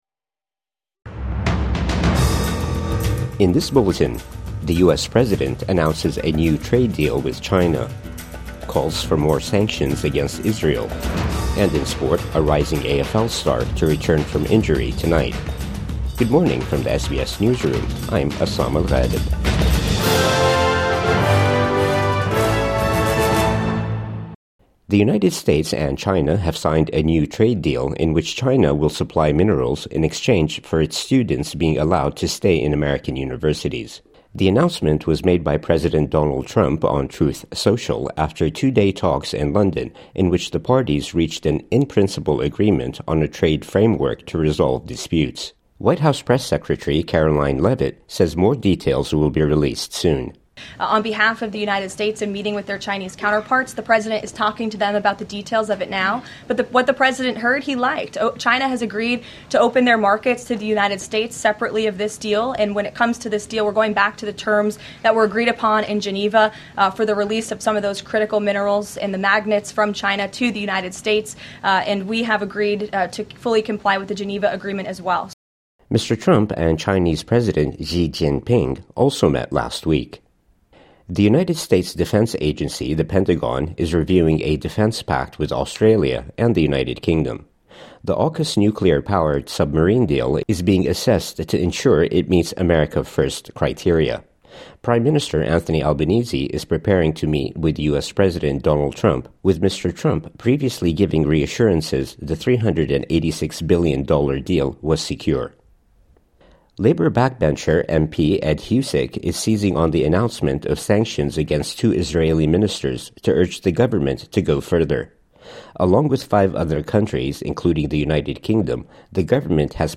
The US president announces a new trade deal with China | Morning News Bulletin 12 June 2025